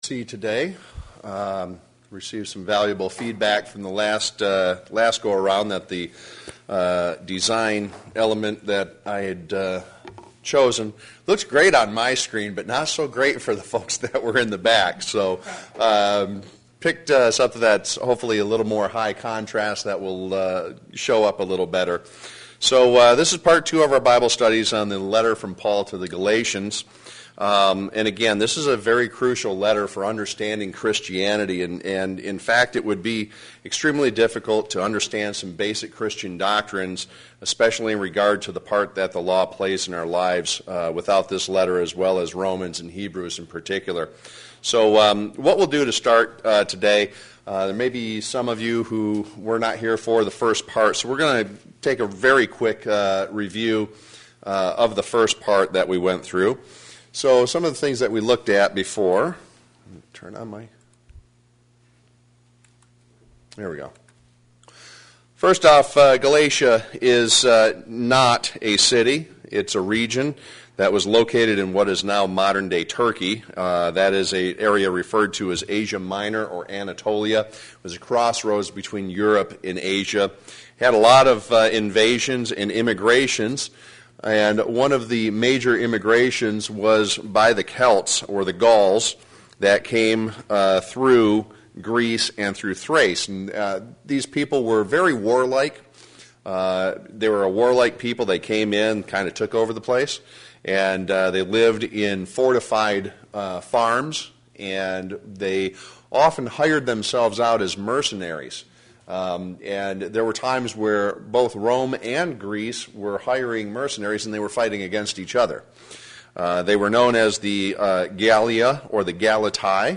Sermons
Given in Flint, MI